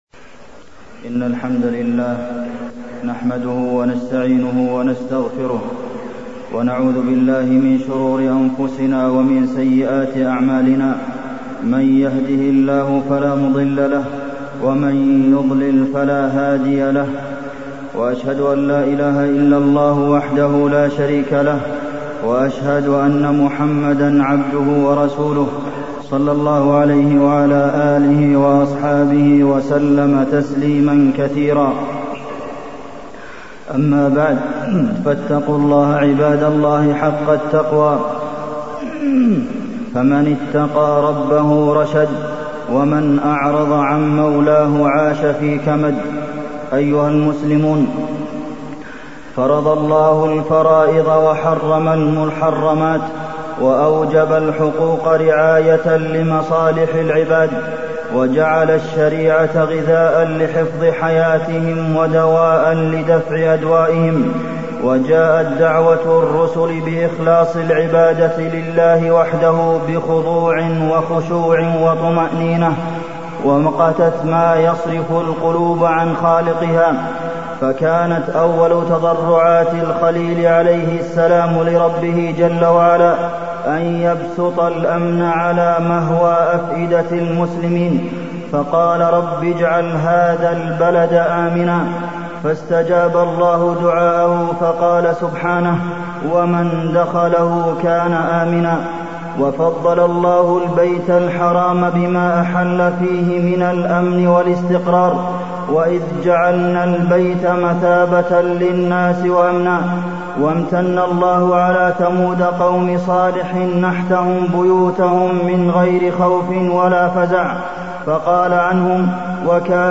تاريخ النشر ٦ جمادى الآخرة ١٤٢٥ المكان: المسجد النبوي الشيخ: فضيلة الشيخ د. عبدالمحسن بن محمد القاسم فضيلة الشيخ د. عبدالمحسن بن محمد القاسم محاسن الشريعة الإسلامية